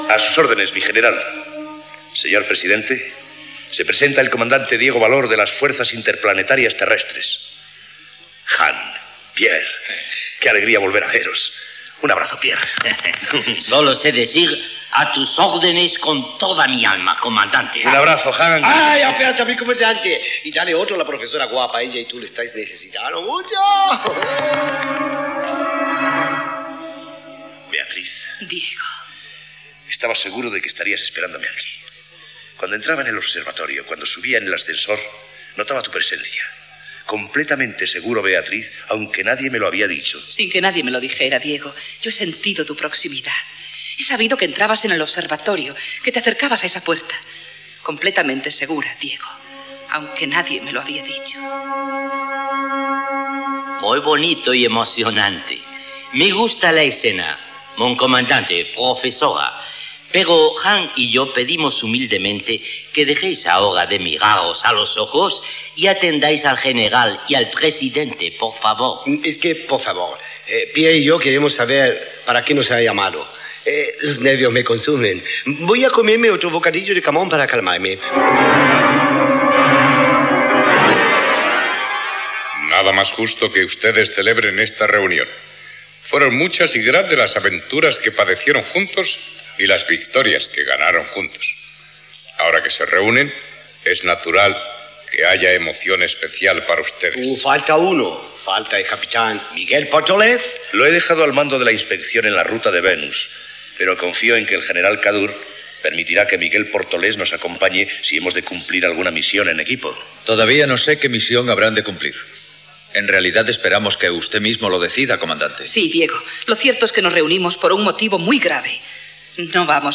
Ficció
Sèrie juvenil emesa per la SER entre 1953 i 1958.